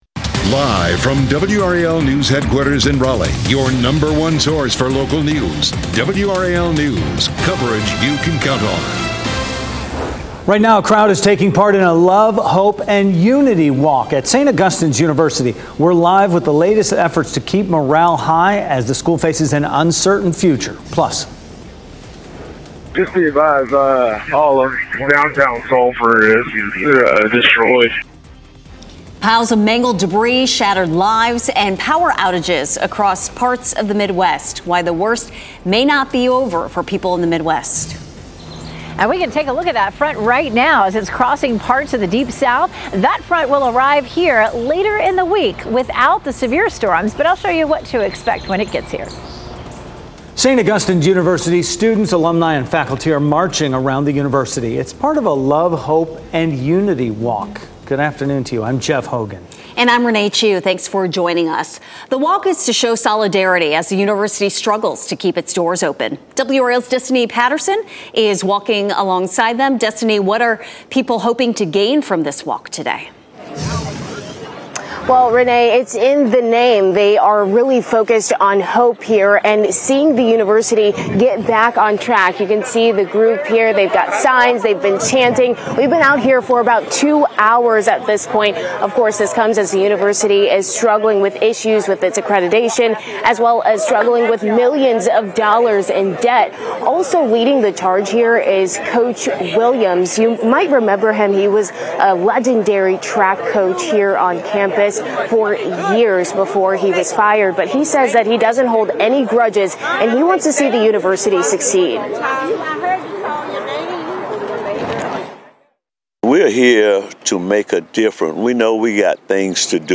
WRAL Newscasts Noon News on WRAL - Monday, April 29, 2024 Apr 29 2024 | 00:43:39 1x 00:00 / 00:43:39 Subscribe Share RSS Feed Share Link Embed